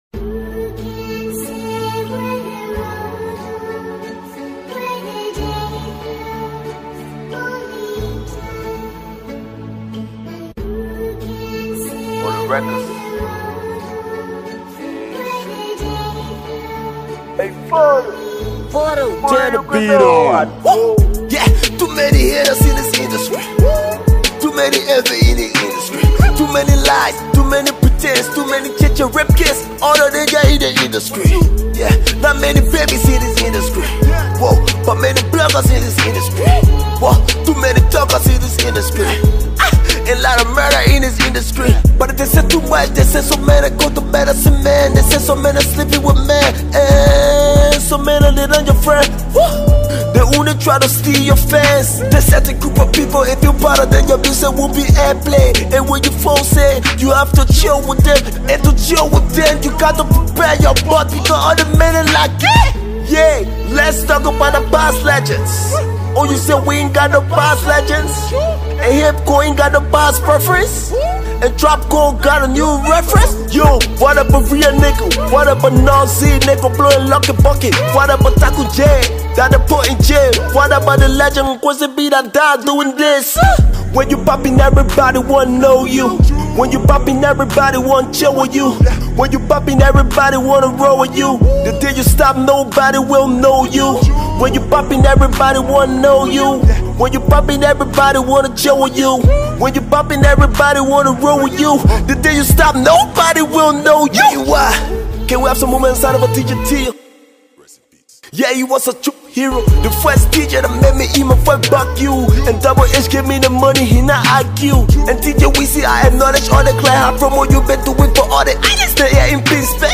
Hip-Hop